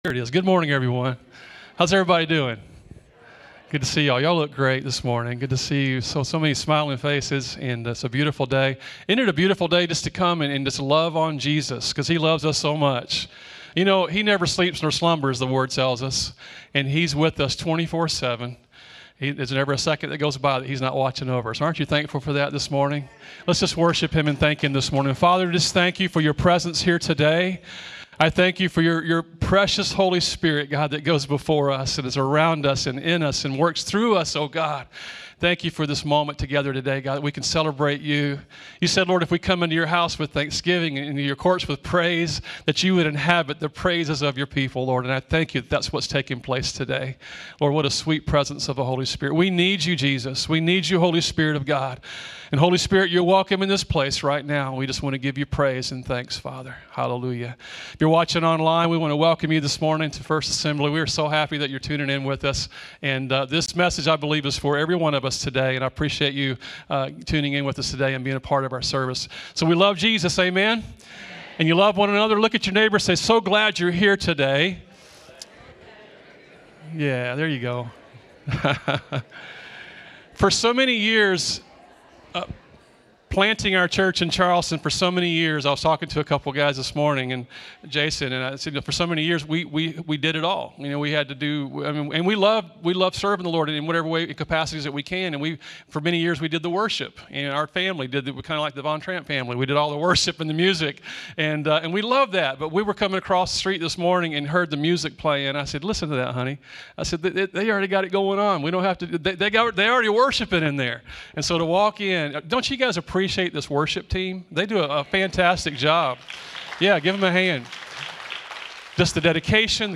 Sermons | First Assembly of God Rock Hill